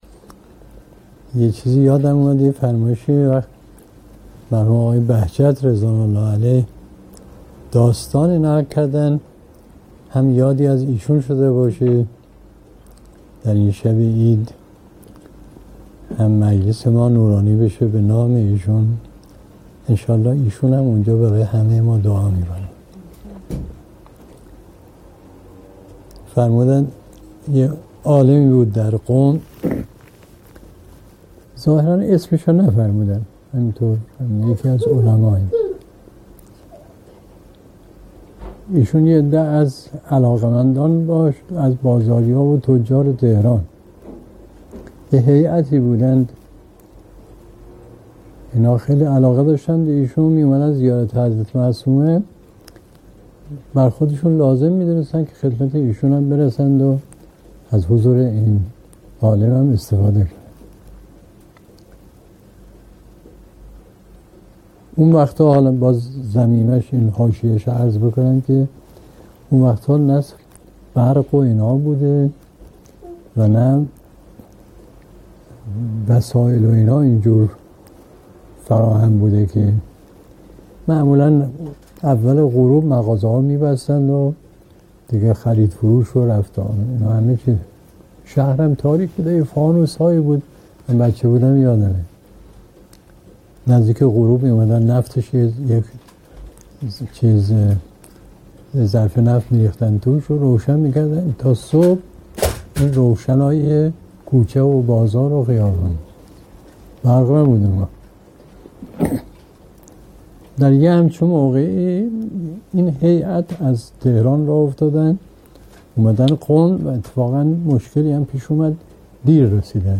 به گزارش خبرگزاری حوزه، مرحوم علامه مصباح در یکی از سخنرانی‌ها به موضوع «برکت نام امیرالمؤمنین علیه السلام» اشاره کردند که تقدیم شما فرهیختگان می‌شود.